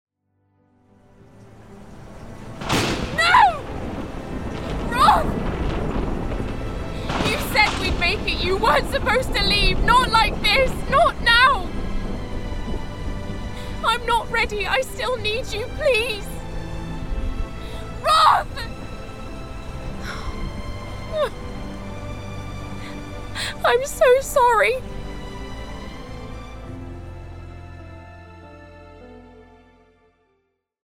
Ariadne RP